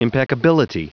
Prononciation du mot impeccability en anglais (fichier audio)
Prononciation du mot : impeccability